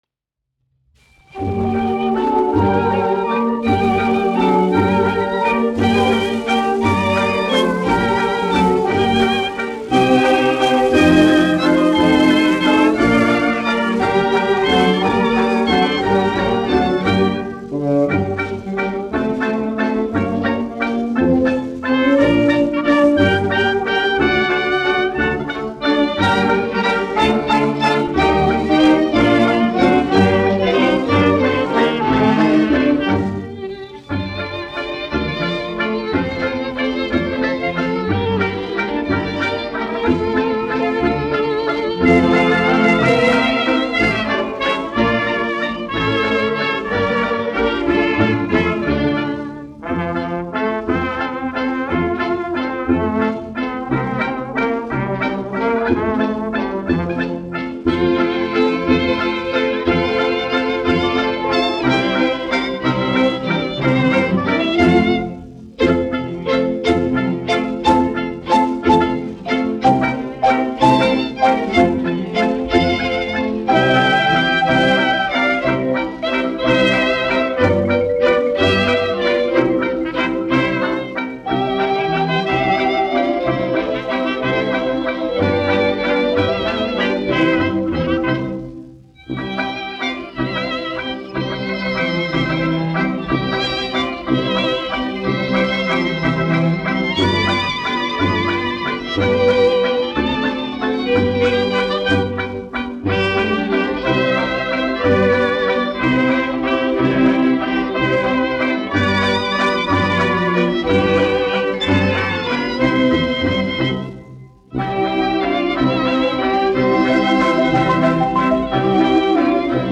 1 skpl. : analogs, 78 apgr/min, mono ; 25 cm
Valši
Orķestra mūzika
Skaņuplate